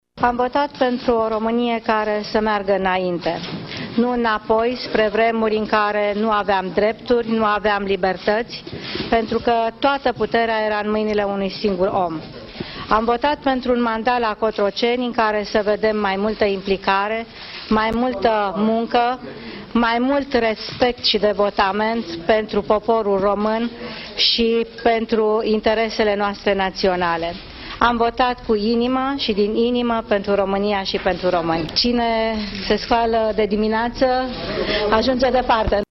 Viorica Dăncilă spune că a votat pentru implicare, muncă și respect: